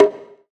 • Tom Single Hit G# Key 02.wav
Royality free tom sample tuned to the G# note. Loudest frequency: 556Hz
tom-single-hit-g-sharp-key-02-DtG.wav